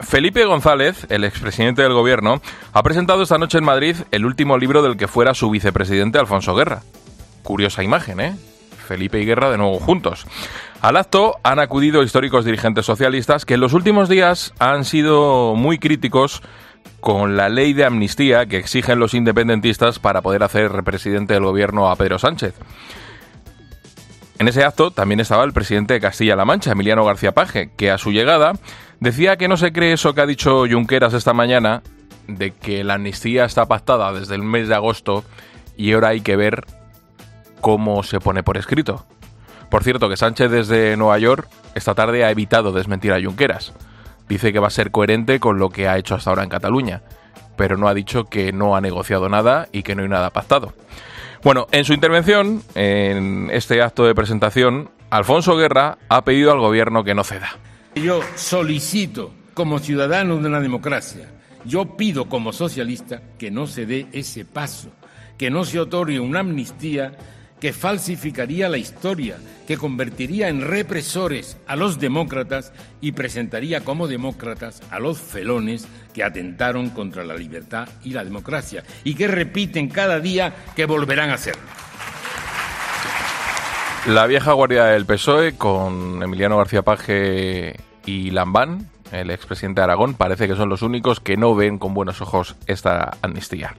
En su intervención en la presentación de su libro 'La rosa y las espinas' este miércoles en el Ateneo de Madrid, Guerra ha dicho que una persona si es de izquierdas "tiene la obligación de no callarse si ve injusticias, arbitrariedades, errores, los vea en la zona conservadora o en la zona progresista".
"No puede callar, tiene que decirlo", ha dicho junto al expresidente del Gobierno Felipe González y entre los aplausos de los asistentes, entre los que figuran socialistas históricos como el exlíder del PSE Nicolás Redondo, recientemente expulsado del PSOE por su "reiterado menosprecio" a las siglas del partido.